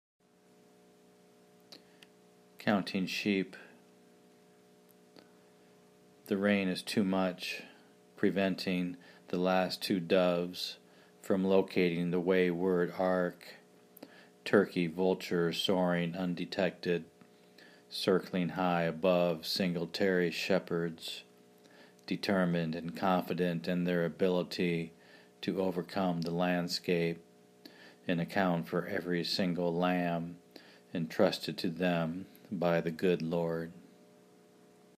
My only suggestion (if you’re open to them) is the audio reading felt a little flat.